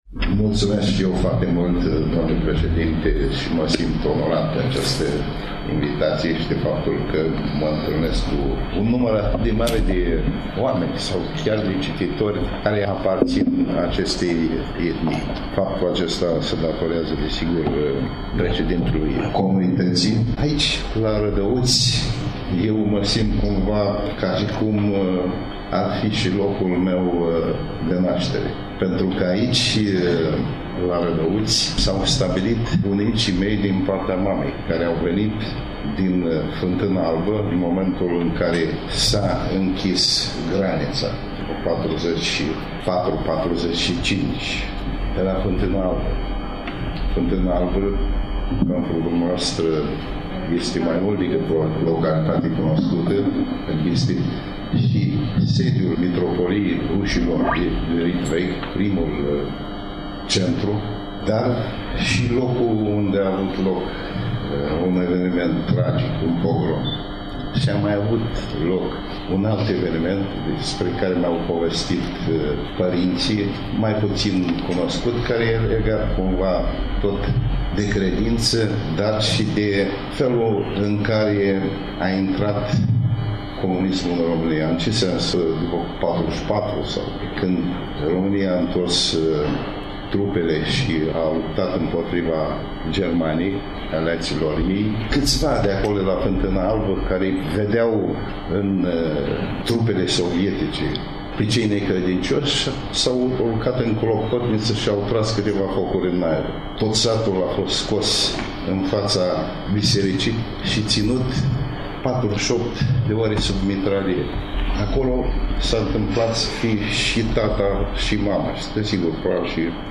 În ediția de astăzi a emisiunii îl ascultăm și pe scriitorul Nichita Danilov, împărtășindu-ne câteva amintiri despre locurile natale, Domnia Sa fiind născut în Climăuți, comuna Mușenița, Suceava, dar ne conturează, pe scurt, o imagine a romanului Ambasadorul invizibil, carte lansată, la Rădăuți, în sala de evenimente a Hotelului Geralds, duminică, 17 februarie, începând cu ora 11.